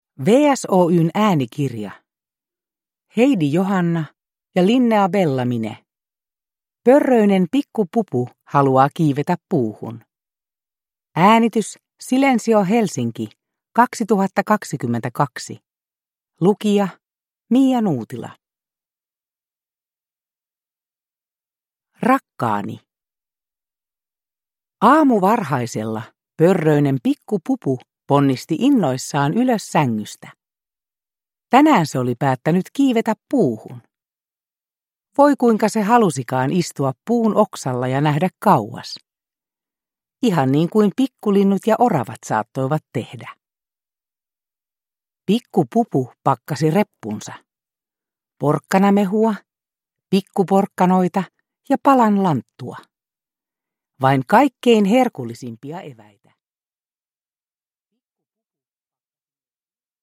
Pörröinen Pikku-Pupu haluaa kiivetä puuhun – Ljudbok – Laddas ner